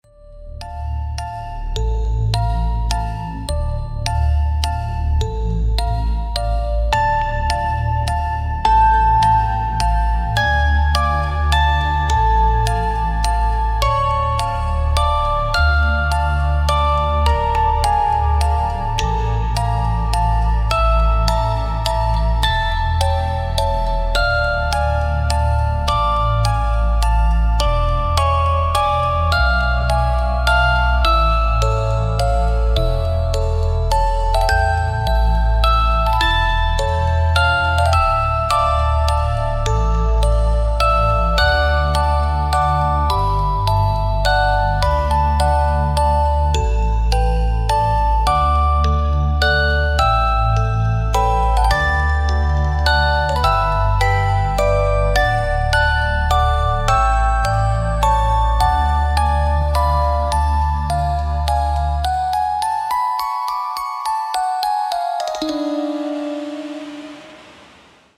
Scary Music Box Halloween Music Box Horror Creepy